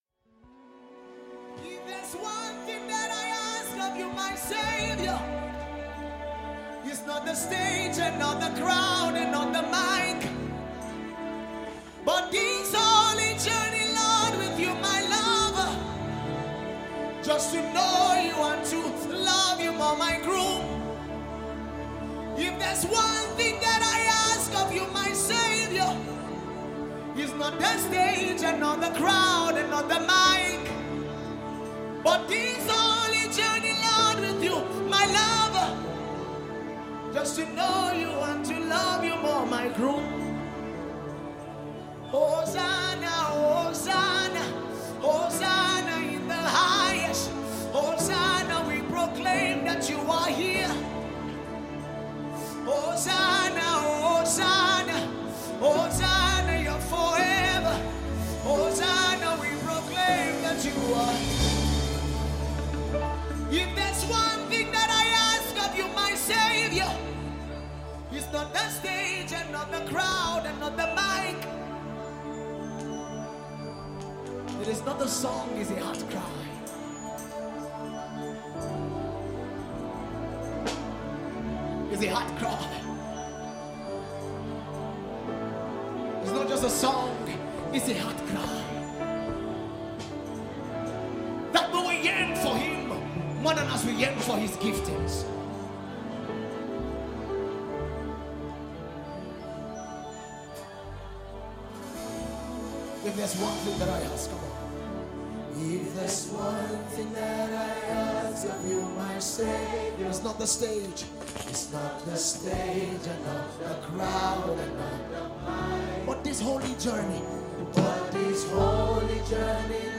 Nigerian gospel